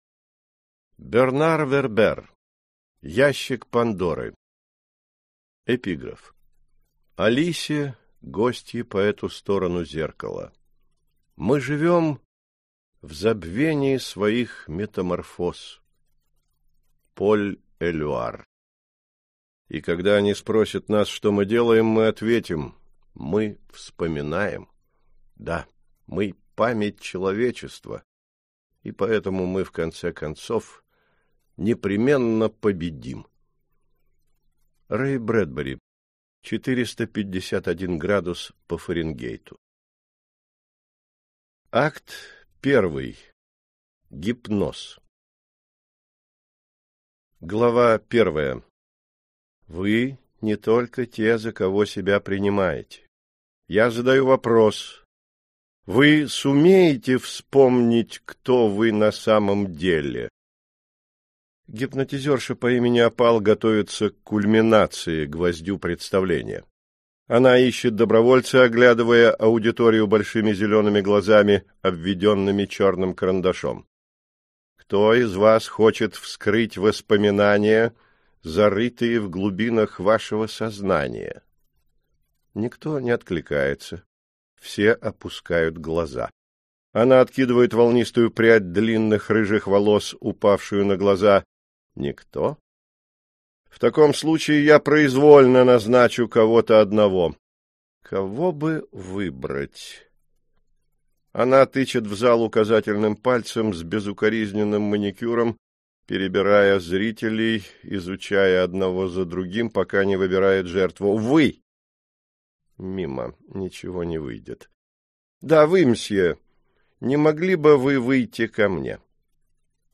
Аудиокнига Ящик Пандоры - купить, скачать и слушать онлайн | КнигоПоиск